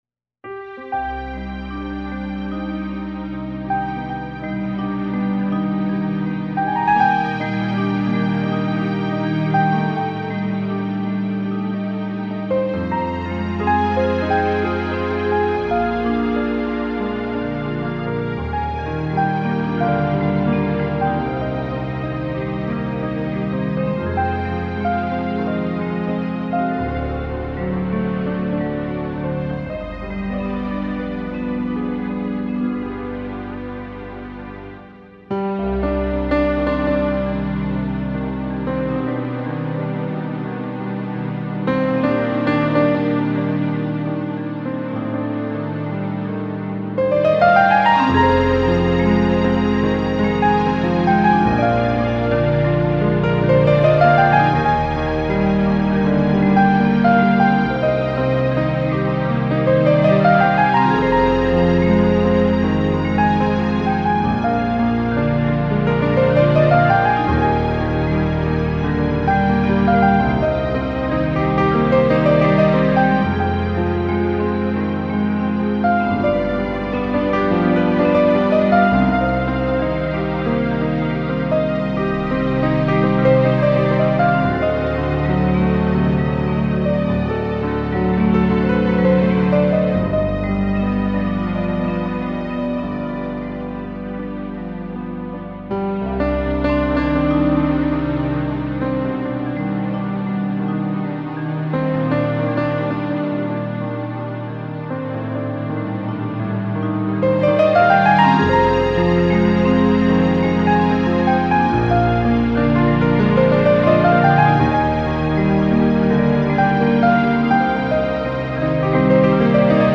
淒美的琴音在滿溢的情境夜裡聽來
慢慢聽吧，相信大夥會跟我一樣，愛上這樣的琴，這樣用情感去敲擊而出的抒情鋼琴。